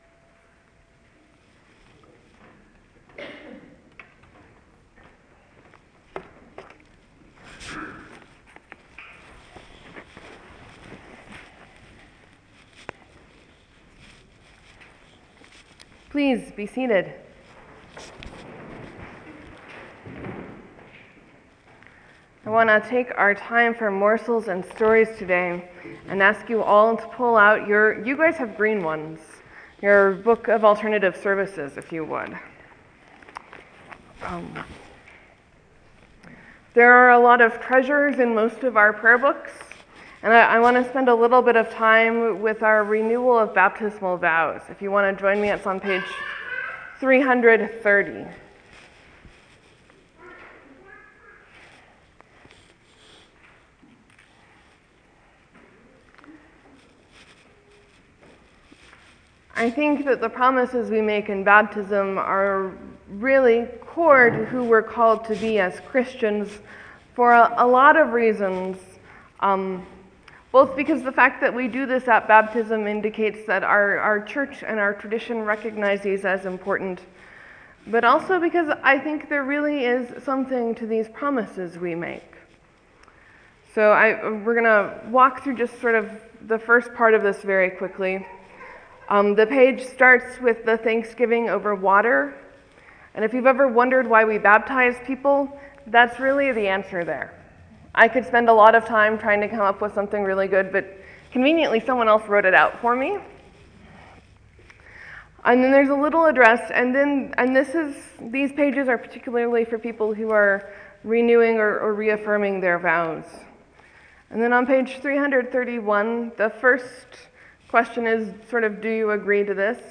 I’ve started doing short education bit before the sermon called Morsels and Stories which is also recorded.